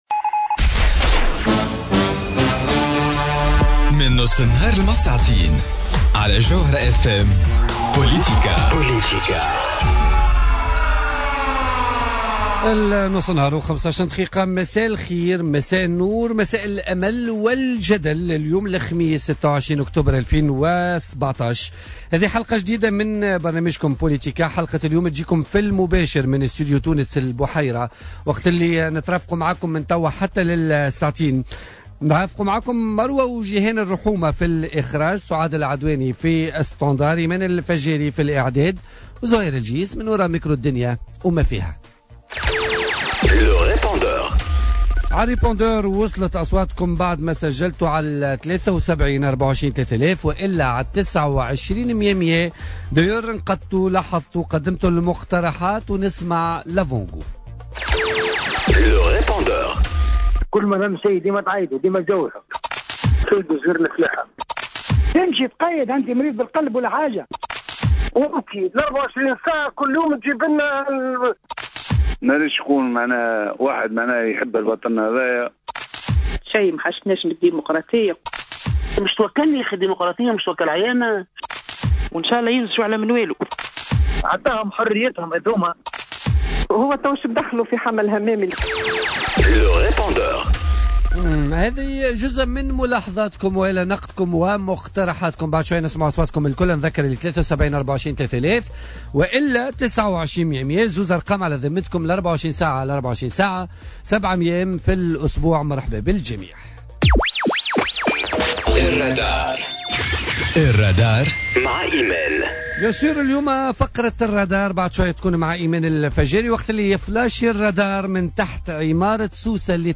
سفيان طوبال ضيف بوليتيكا